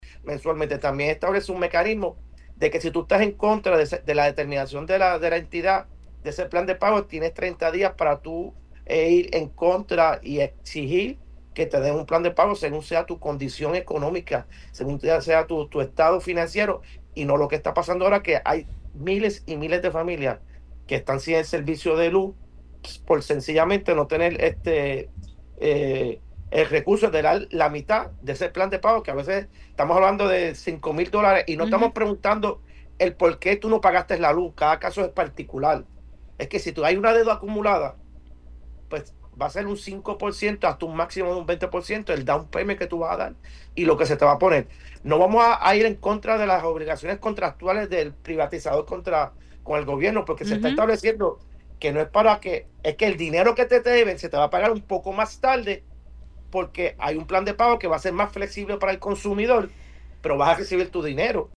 No vamos a ir en contra de las obligaciones contractuales del privatizador con el Gobierno porque se está estableciendo que […] el dinero que te deben se te va a pagar un poco más tarde porque hay un plan de pago que va a ser más flexible para el consumidor, pero vas a recibir tu dinero“, agregó el legislador novoprogresista.